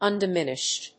音節un・di・min・ished 発音記号・読み方
/`ʌndɪmínɪʃt(米国英語)/